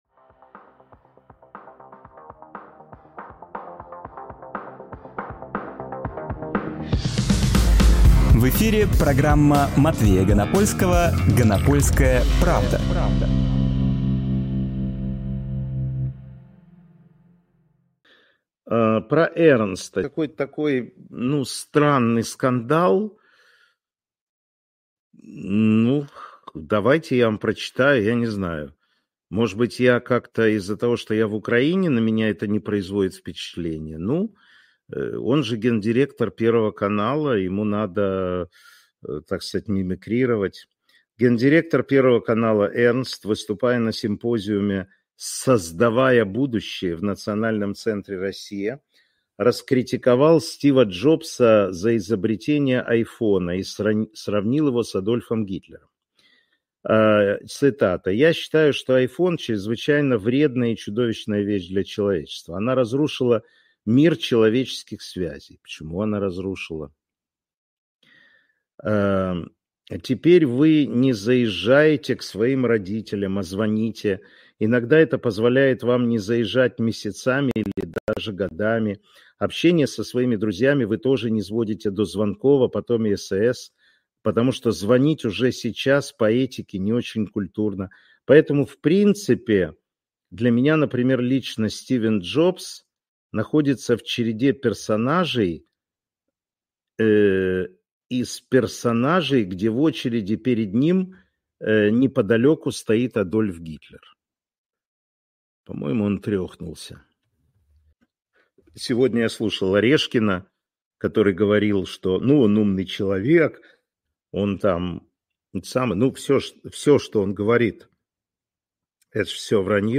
Фрагмент эфира от 05 ноября